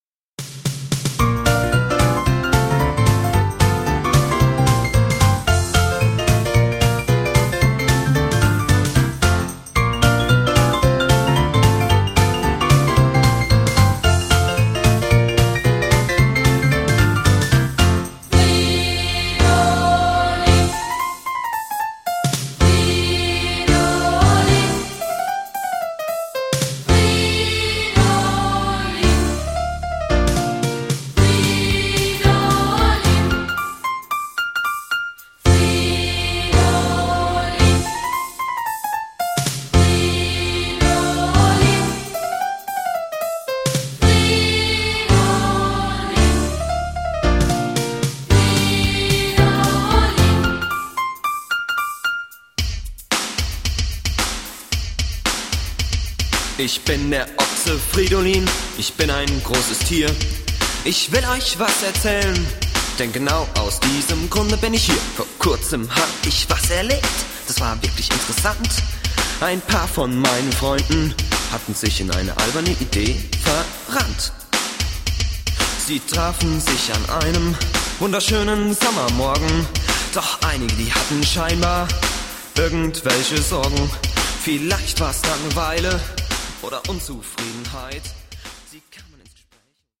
Ein Musical für Kinder